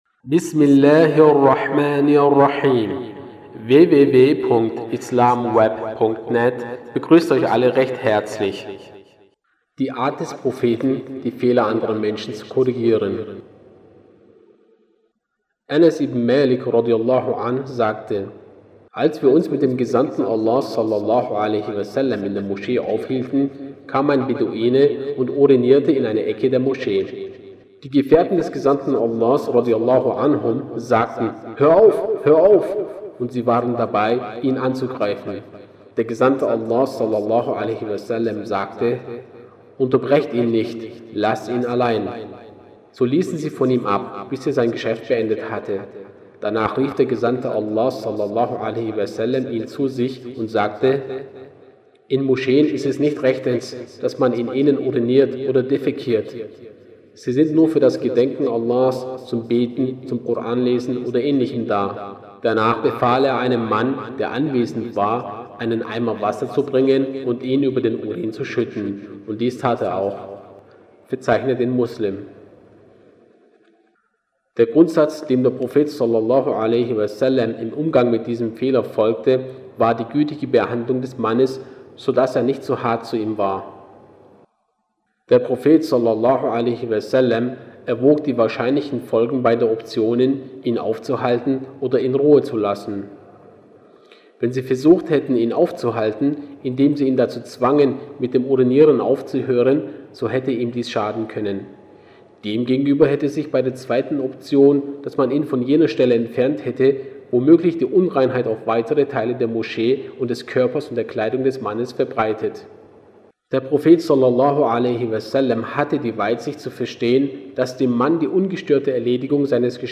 Lesungen